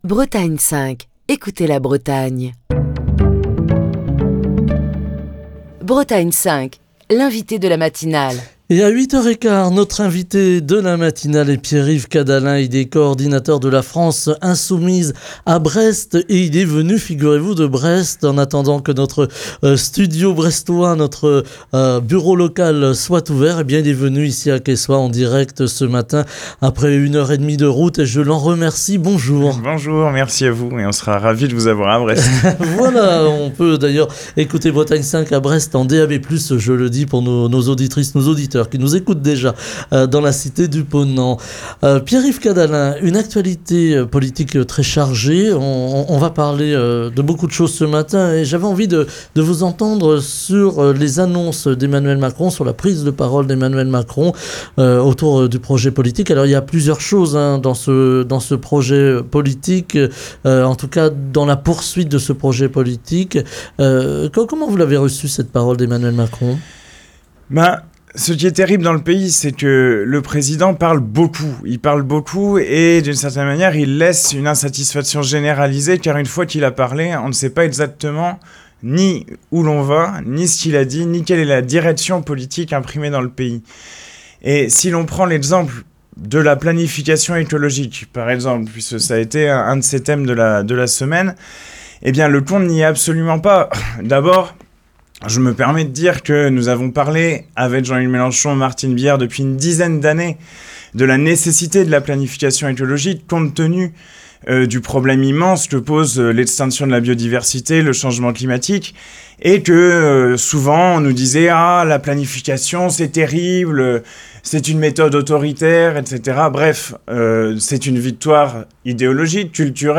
Émission du 27 septembre 2023. Ce ne sont pas les sujets qui manquent en cette rentrée parlementaire, où la France Insoumise se saisit des grands dossiers du moment.